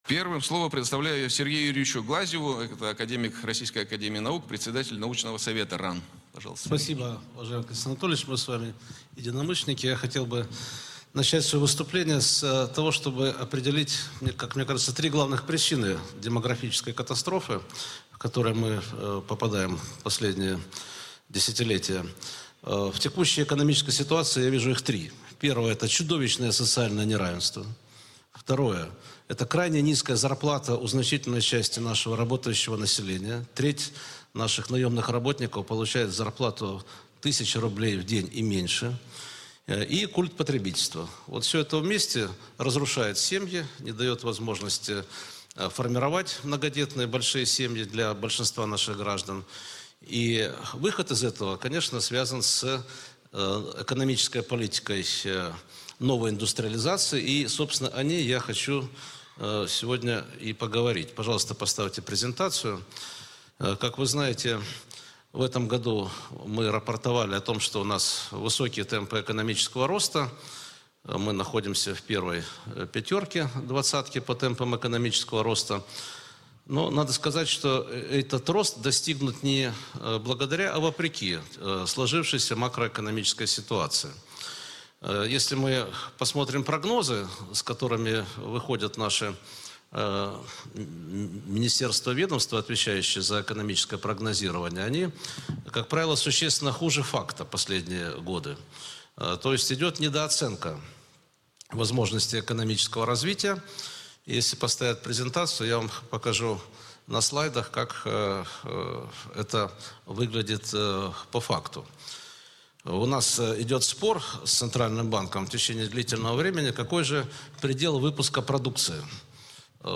Выступление Сергея Глазьева, академика РАН, председателя научного совета РАН Москва. 1 апреля 2025 года.